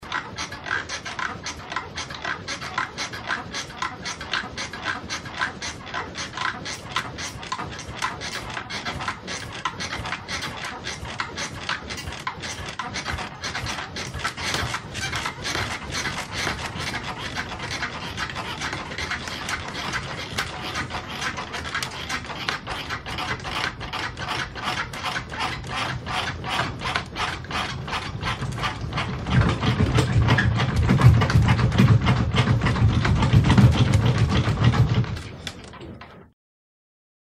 Звуки кровати
Кровать скрипит все быстрее